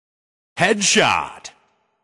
headshot-cutmp3.mp3